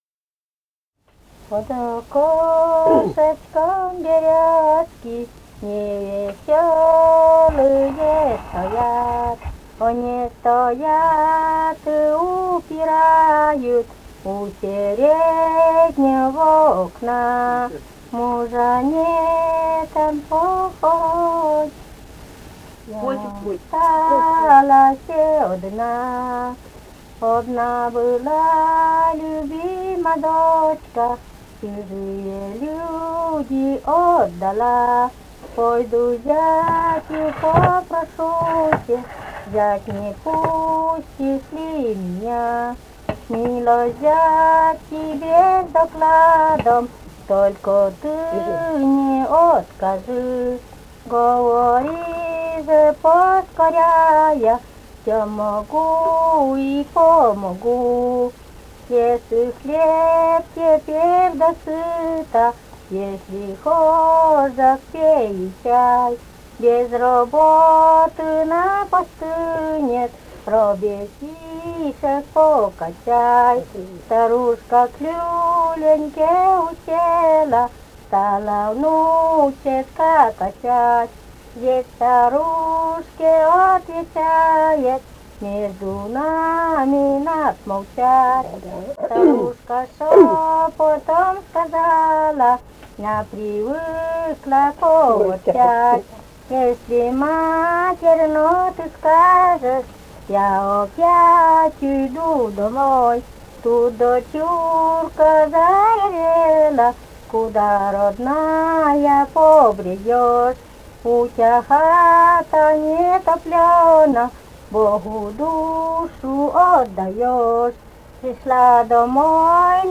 «Под окошечком берёзки» (лирическая).